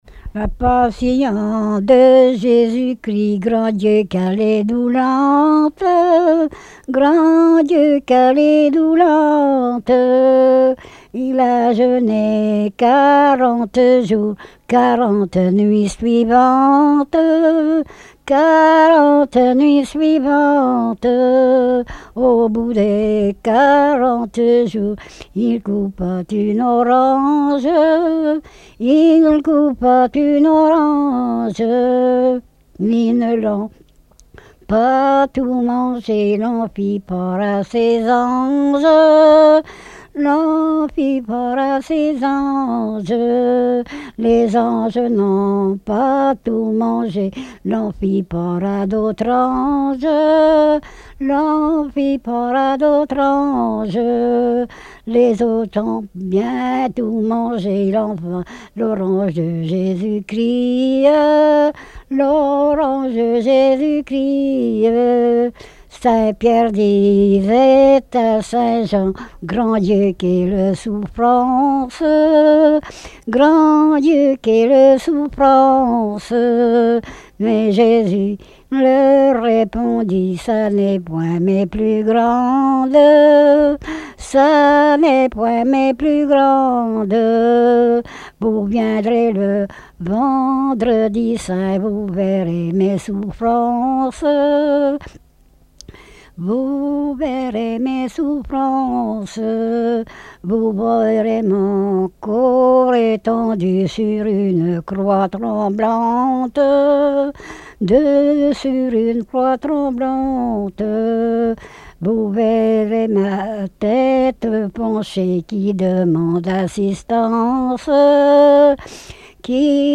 circonstance : quête calendaire
Répertoire de chants brefs et traditionnels
Pièce musicale inédite